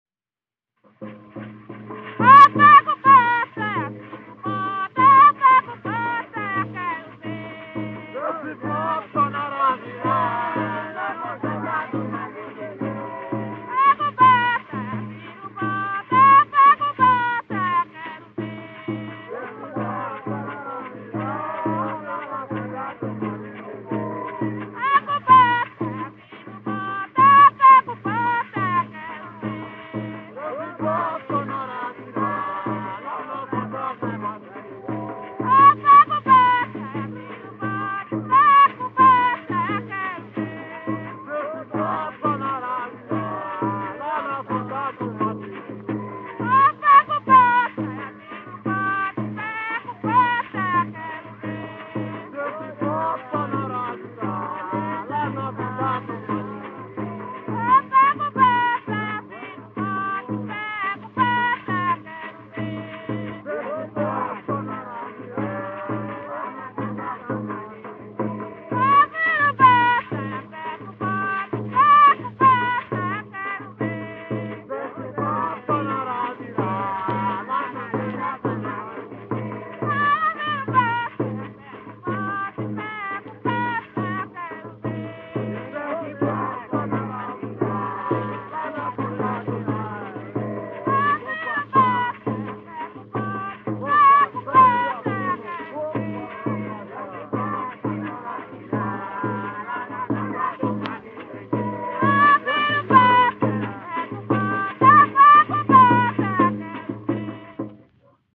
Coco embolada